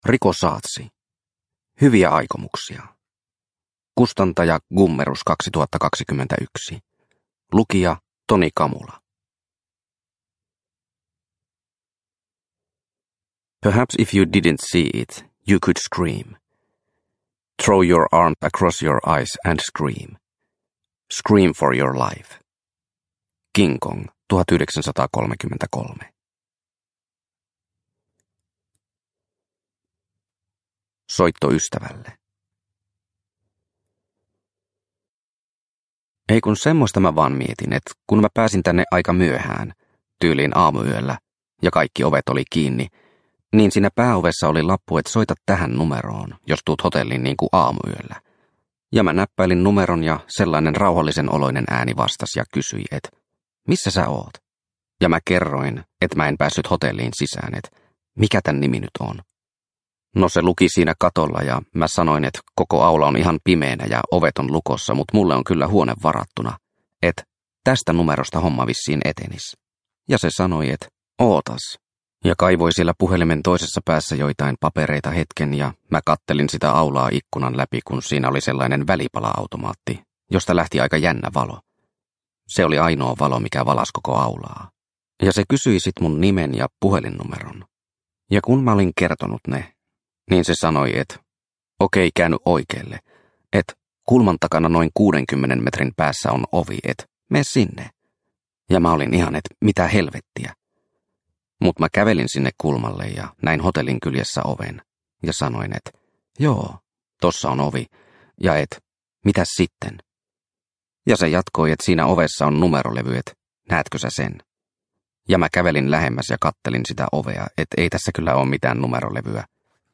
Hyviä aikomuksia – Ljudbok